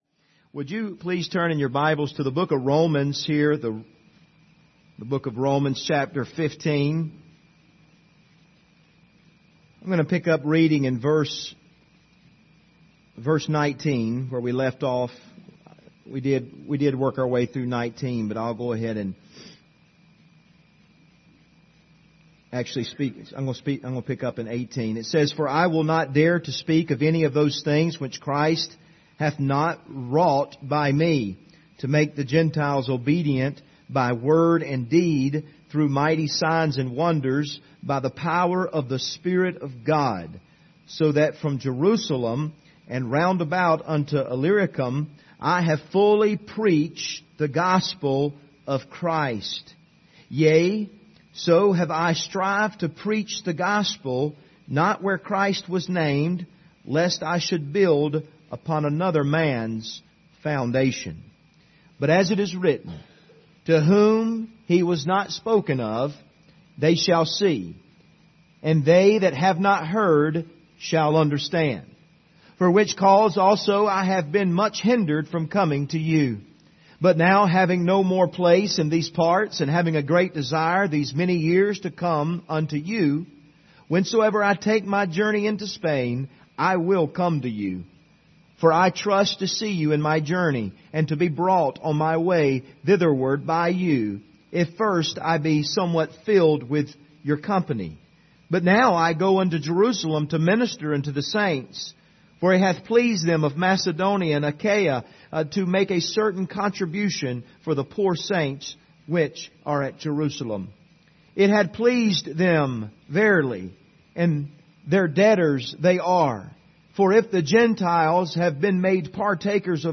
Service Type: Sunday Evening Topics: evangelism , missions